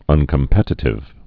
(ŭnkəm-pĕtĭ-tĭv)